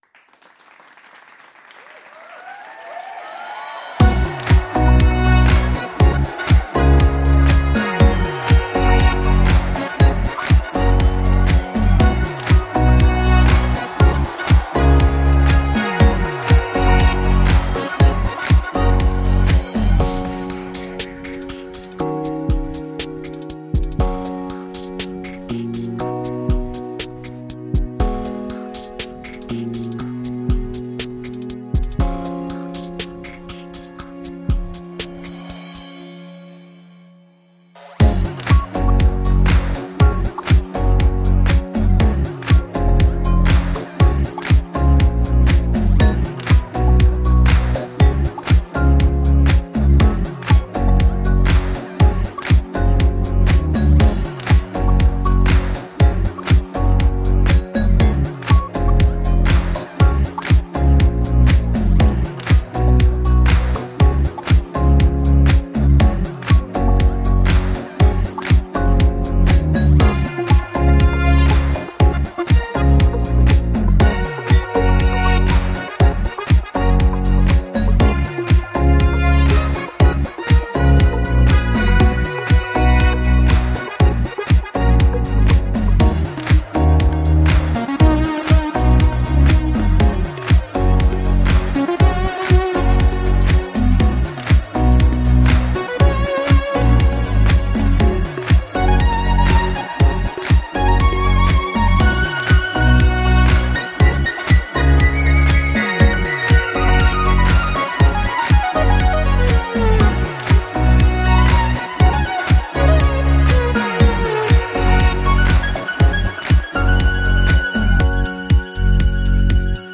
Dziesmas pavadījums atrodams audio pielikumā raksta augšpusē.